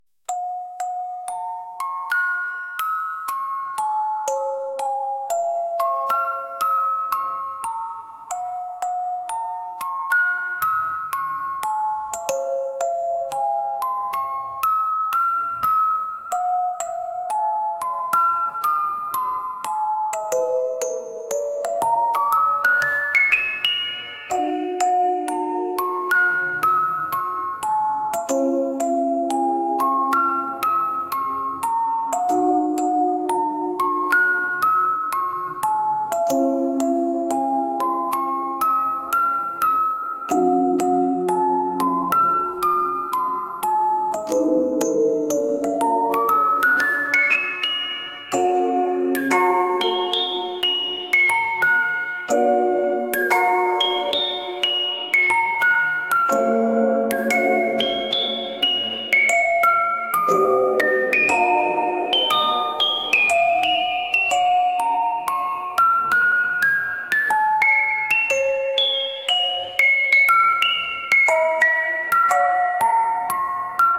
「オルゴール」